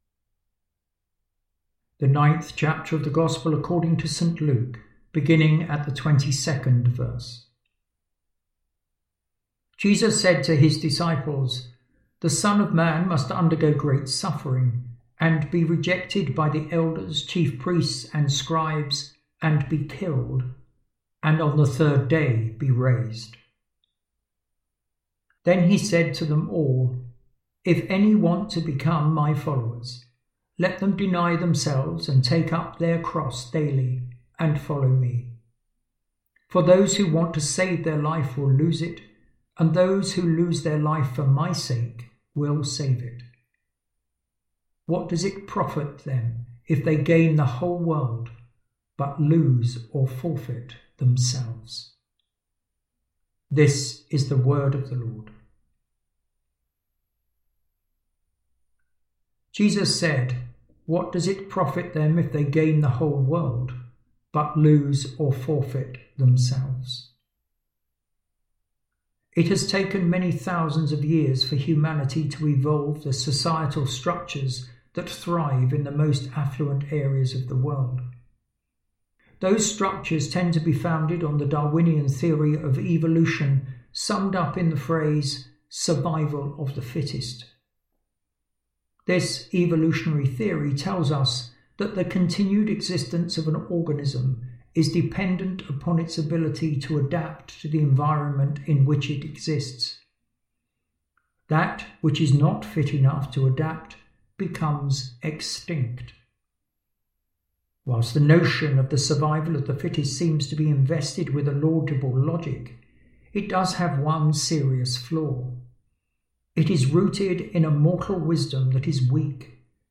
Reflection on Luke 9.22-25